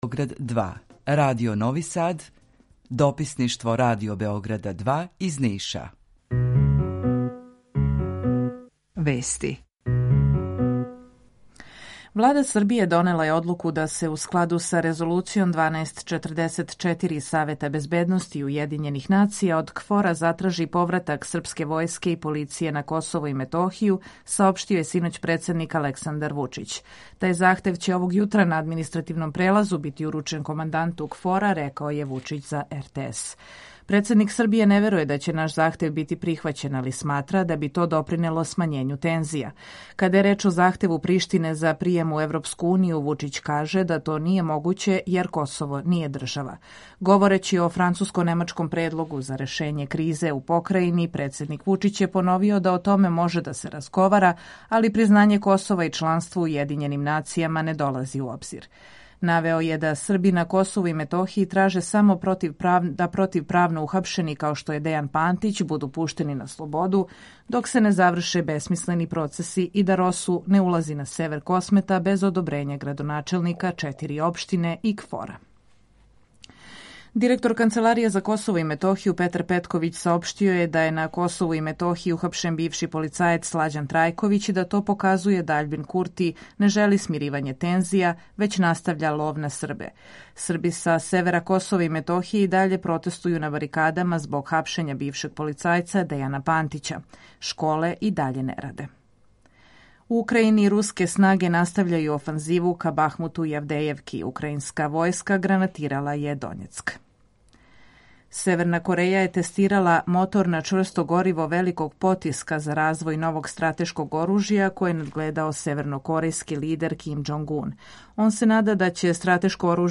Емисију реализујемо уживо ‒ заједно са Радиом Републике Српске у Бањалуци и Радио Новим Садом
У два сата, ту је и добра музика, другачија у односу на остале радио-станице.